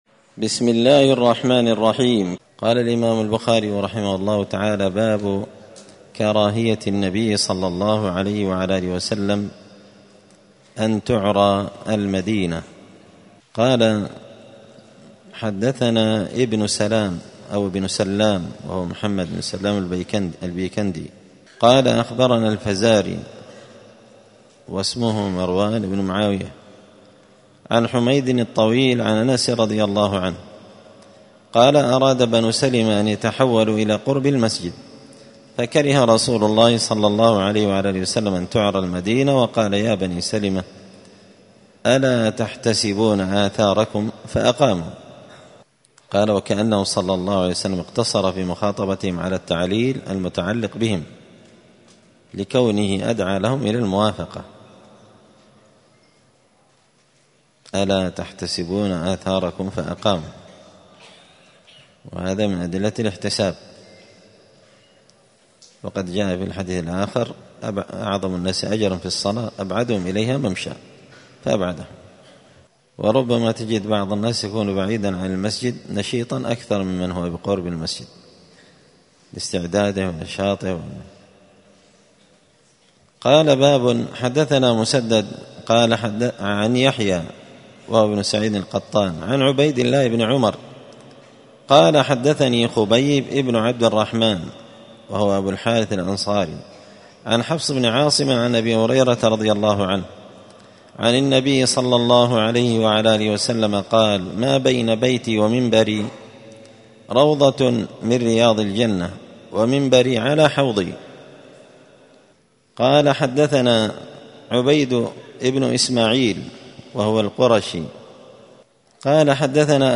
دار الحديث السلفية بمسجد الفرقان قشن المهرة اليمن